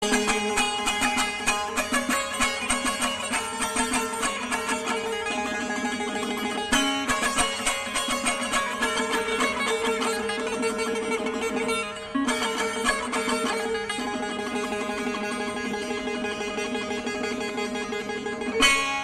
مراسم اجرای موسیقی سنتی خراسان با دو تار | سایت ایرانیان پرتغال
این ساز به صورت ضربی نواخته نمی شود بلکه با ناخن، به اصطلاح زخمه زده می‌شود. دوتارِ شمال خراسان دارای کاسه‌ای گلابی‌شکل و دسته‌ای نسبتاً بلند و دو رشته سیم (تار) است.
2tar-iranian.pt_.mp3